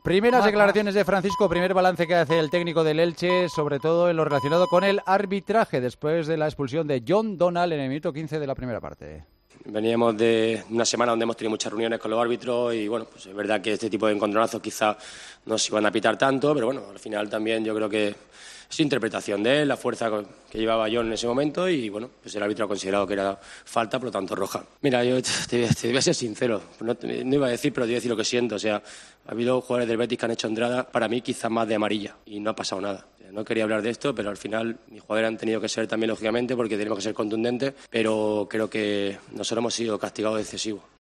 El técnico recordaba que "veníamos de una semana de muchas reuniones con los árbitros, y la verdad es que este tipo de 'encontronazos' quizás no se iban a pitar tanto. Pero al final creo que también es interpretación del árbitro, y también hay que ver la velocidad que llevaba John en ese momento. Al final, el árbitro ha considerado que es falta y, por lo tanto, roja", explicó en la posterior rueda de prensa del partido en el que el Elche perdió por 3-0.